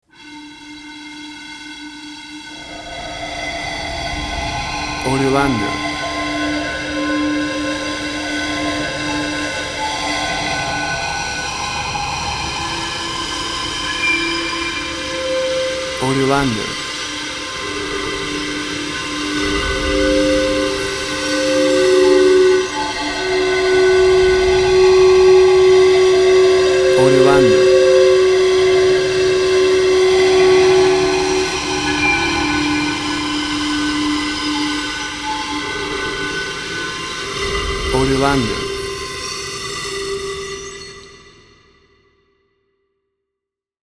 Ethereal and windy music with zampoñas.
WAV Sample Rate: 24-Bit stereo, 48.0 kHz
Tempo (BPM): 60